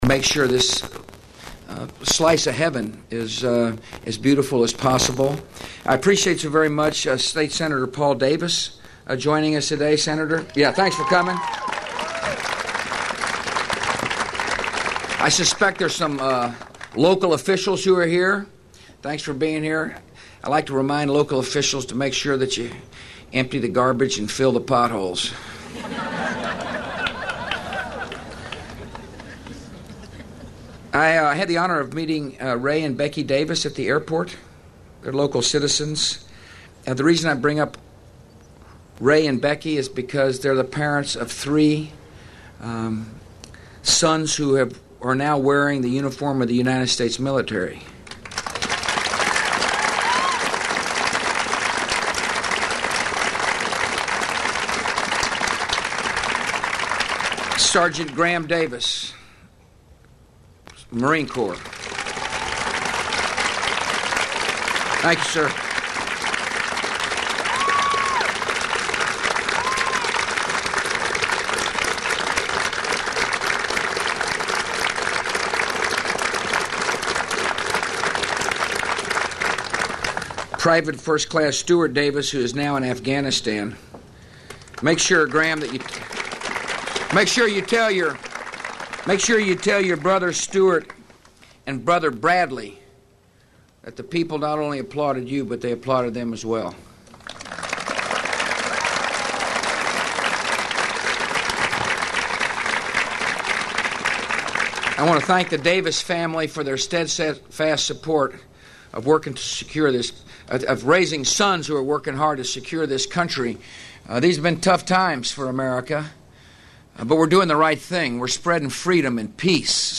Bush_EarthDay2004_linear.mp3